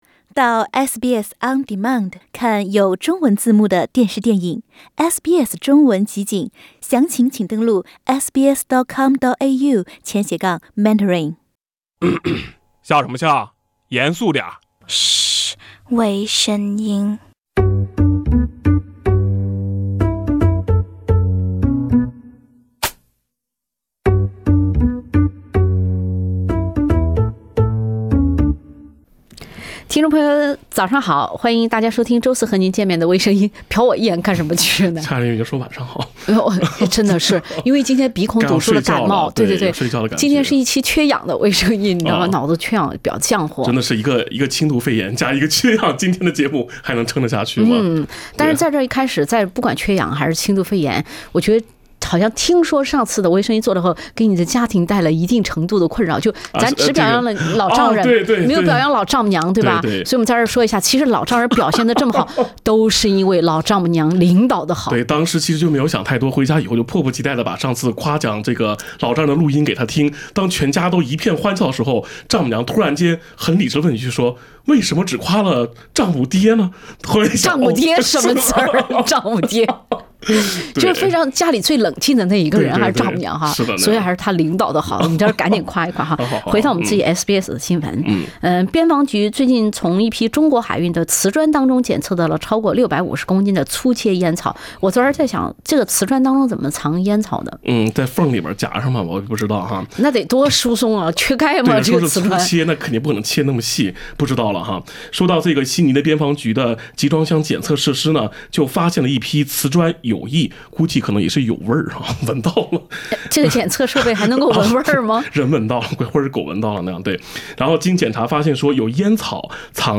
另类轻松的播报方式，深入浅出的辛辣点评，更劲爆的消息，更欢乐的笑点，敬请收听每周四上午8点30分播出的时政娱乐节目《微声音》。